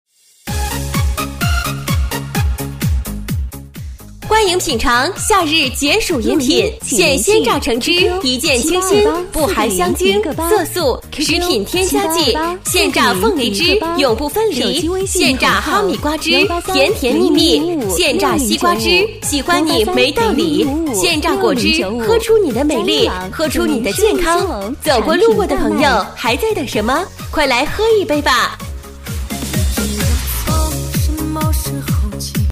H399 鲜榨果汁广告录音-女声.mp3